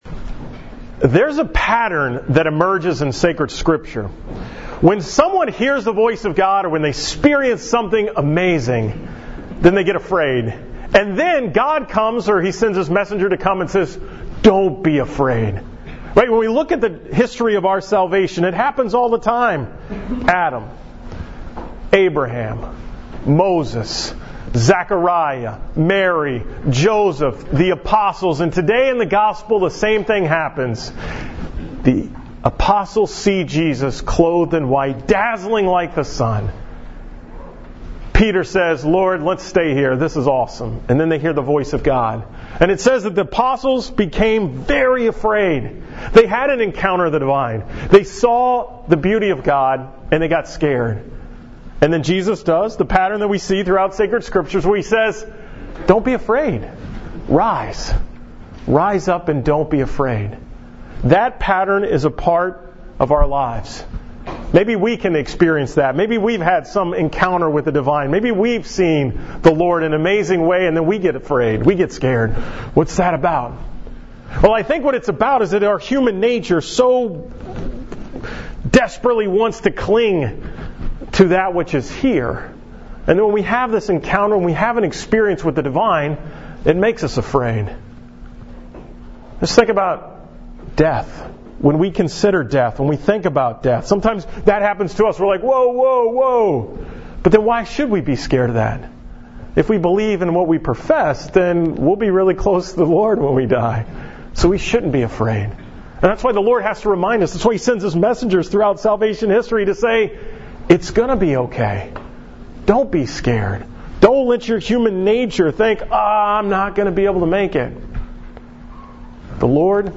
From the Mass at Rice University on March 12, 2017